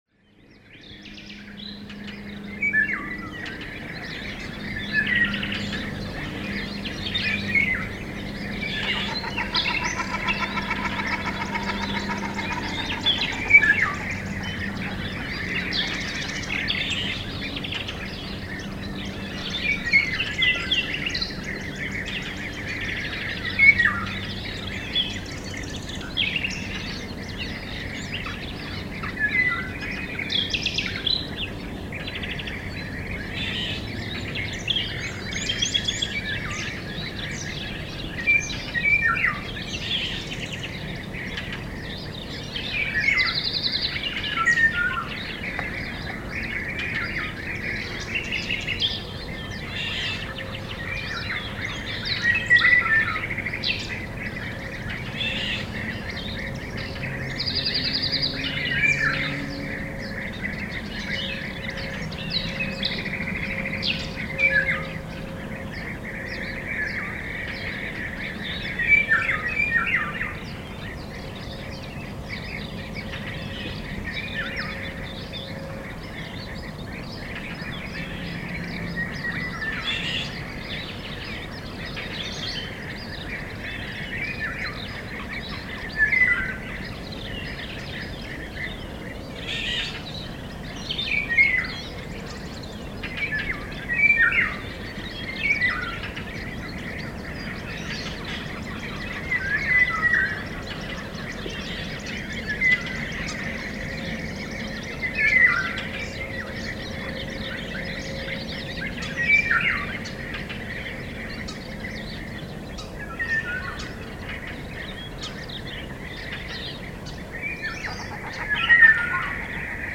Ce très beau lac est baigné du chant des loriots tôt le matin.
loriots.mp3